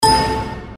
без слов
короткие
электронные
Системный звук ошибки Windows